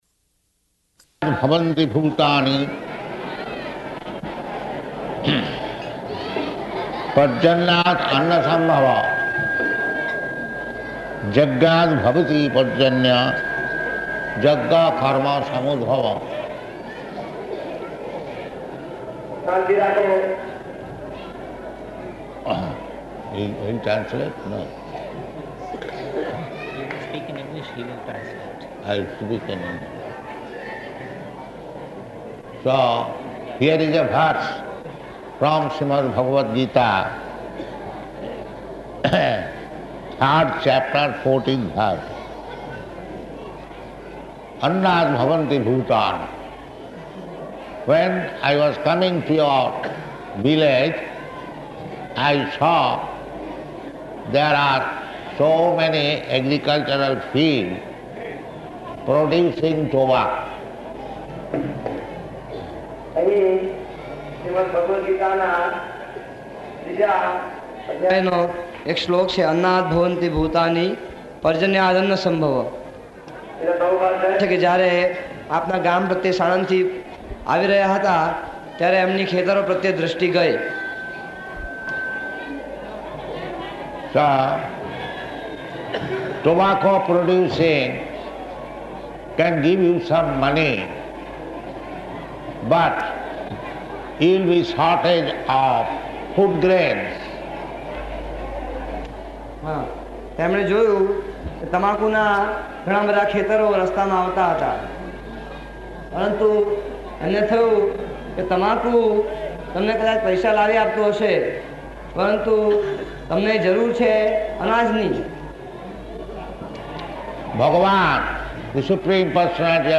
Bhagavad-gītā 3.14 [with translator]